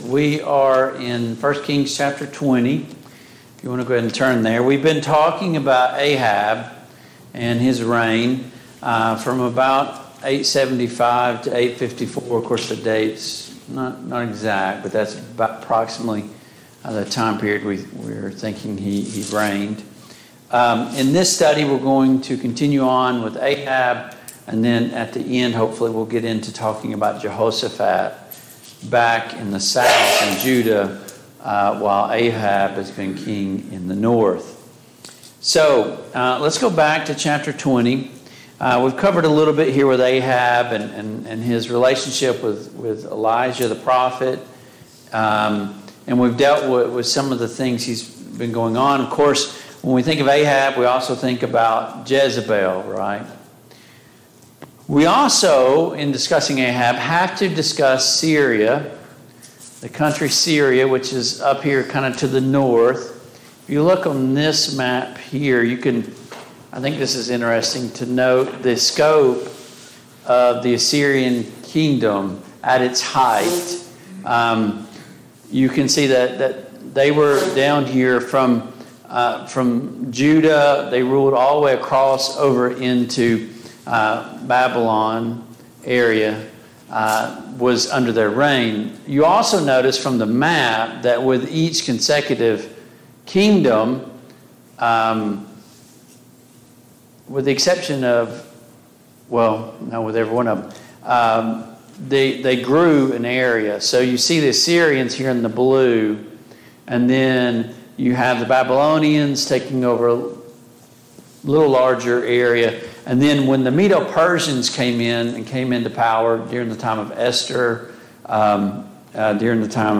The Kings of Israel Passage: 1 Kings 20, 1 Kings 21 Service Type: Mid-Week Bible Study Download Files Notes Topics